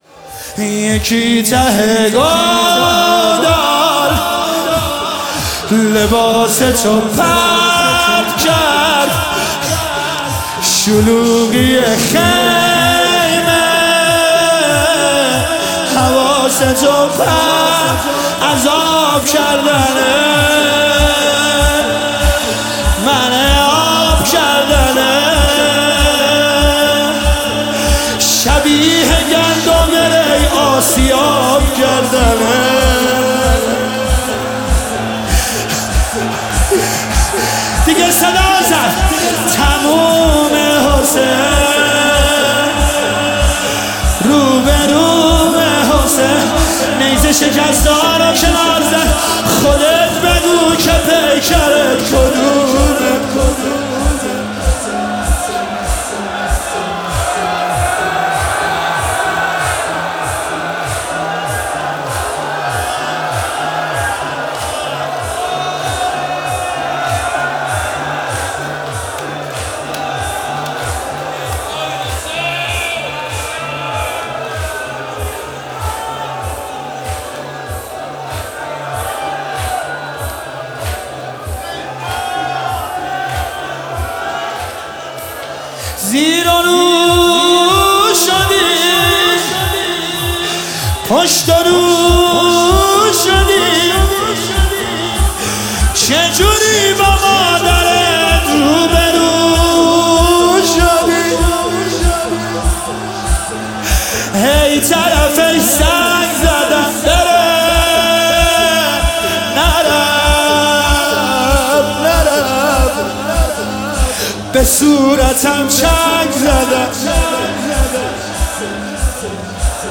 مداحی لطمه زنی شب 21 ماه رمضان شب قدر